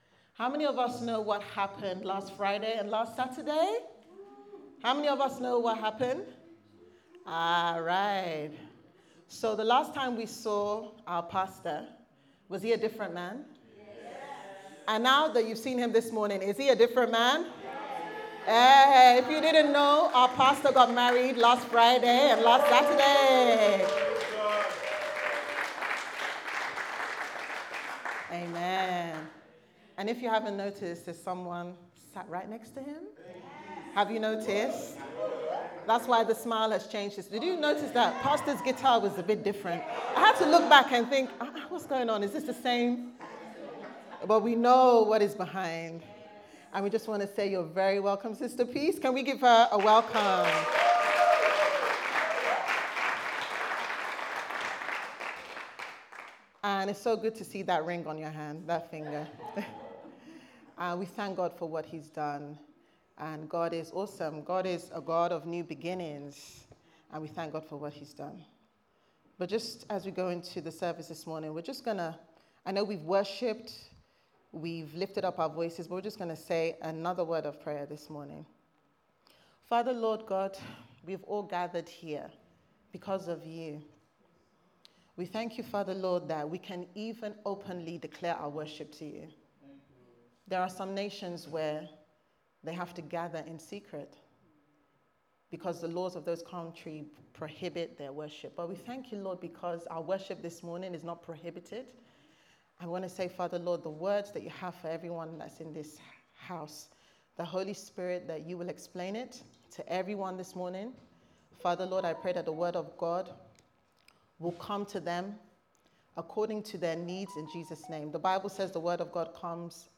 Service Type: Sunday Service Sermon